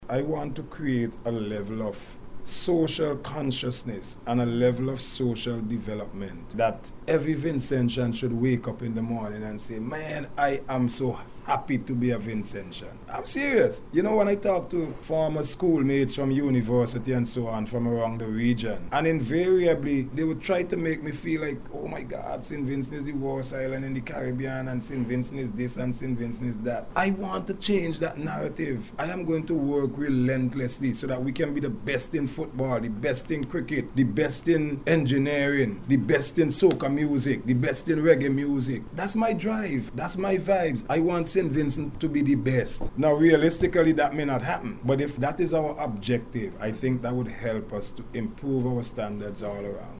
In a recent interview with NBC News, Minister Bramble said he is determined to reshape the narrative surrounding Saint Vincent and the Grenadines, fostering a culture of excellence and national pride across all sectors — from sports and culture to innovation and industry.